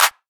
YM Clap 12.wav